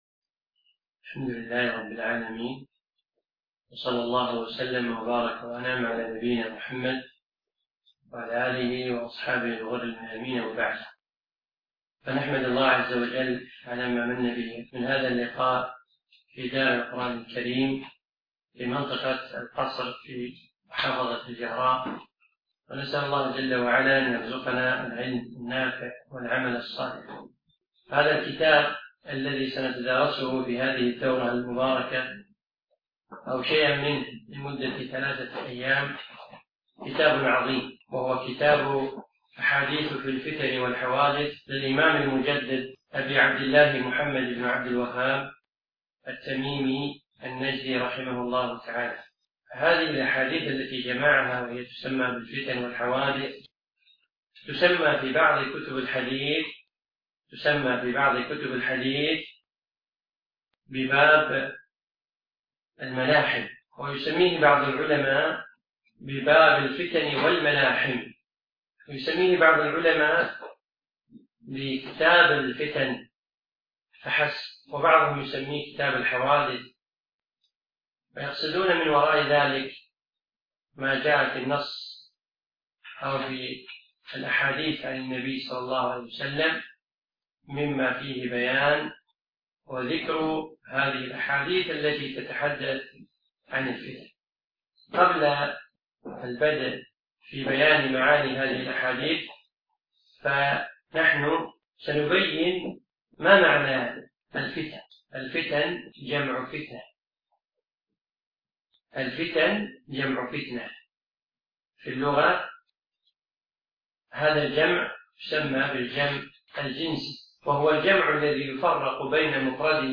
أقيمت الدورة في مركز القصر نساء مسائي
الدرس الأول